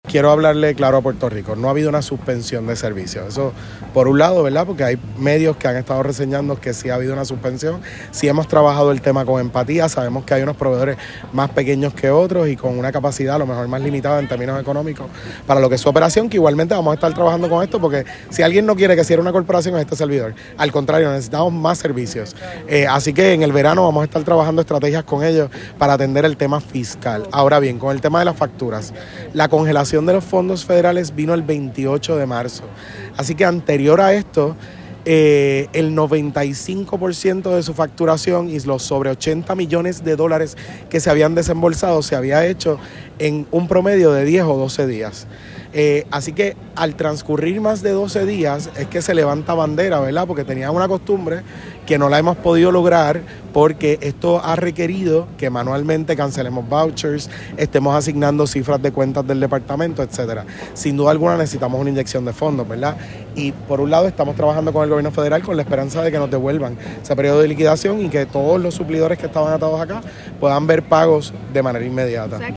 Las expresiones del secretario se dieron durante la congregación de bandas escolares de toda la isla, las cuales fueron desfilando por toda la avenida Carlos Chardón, en ruta hacia el DE en plena semana educativa.